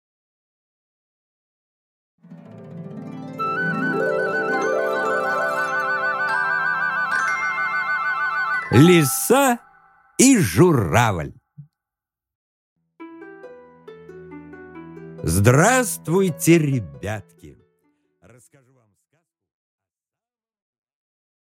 Аудиокнига Лиса и Журавль | Библиотека аудиокниг